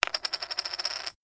chip3.mp3